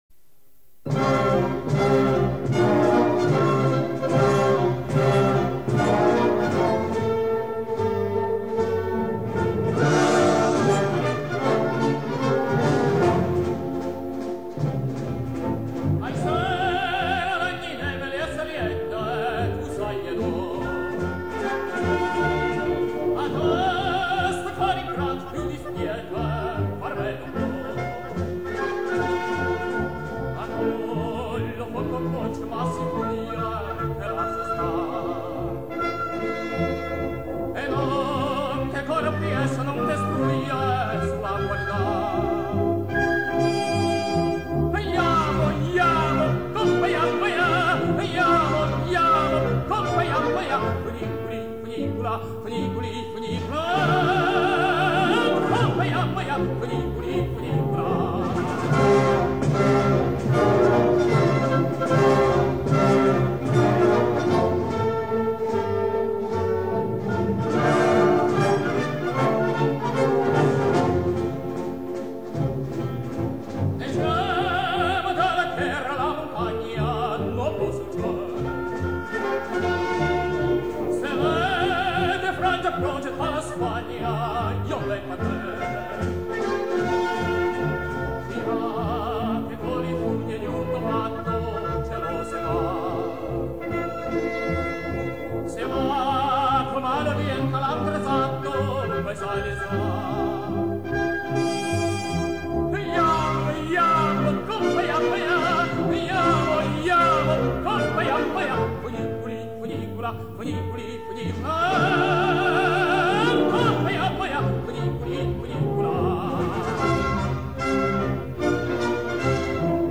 他熟练掌握了意大利美声学派传统唱法里中、高声区衔接过渡平稳的技巧，并使歌声中具有了强烈的穿透力和金属光泽。
作者采用快速的六拍子，增强了歌曲的律动感，生动地刻画出缆车上的人们兴奋得意的表情。